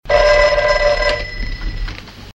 • old distorted rotary phone.wav